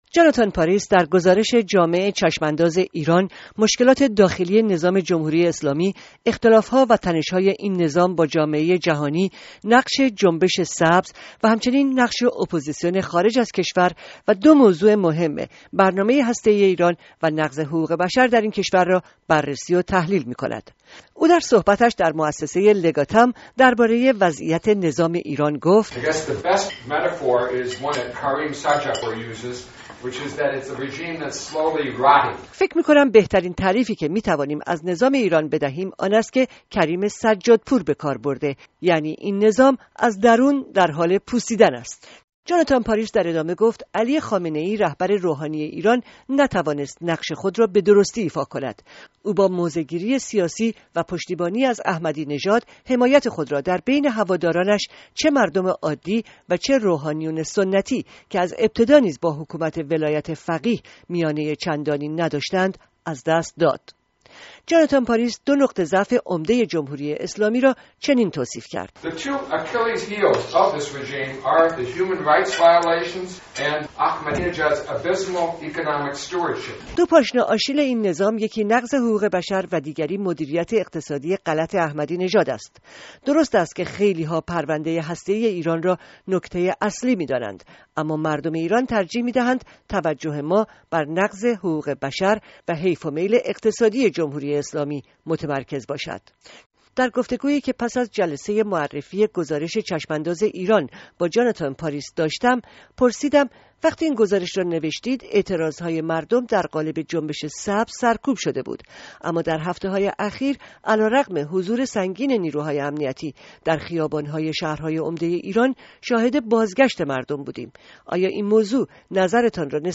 گفت‌وگوی رادیوفردا